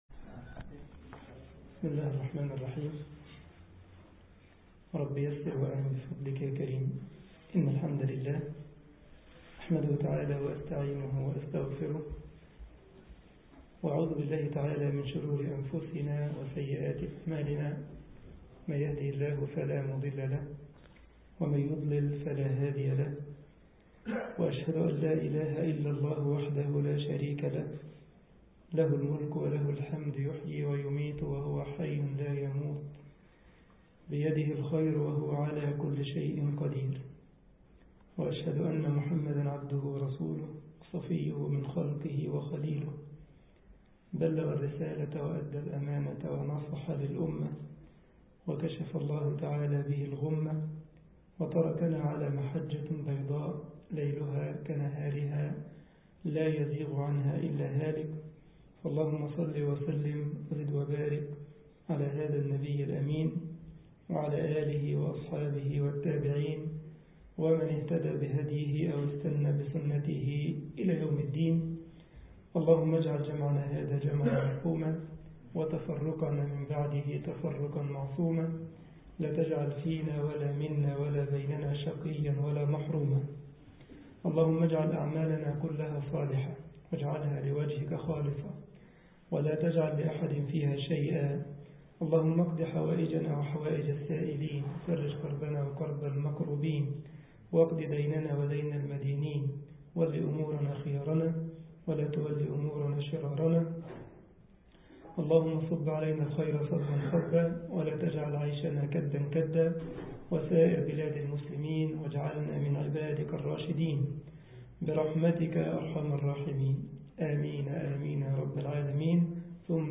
مسجد الجمعية الإسلامية بكايزرسلاوترن ـ ألمانيا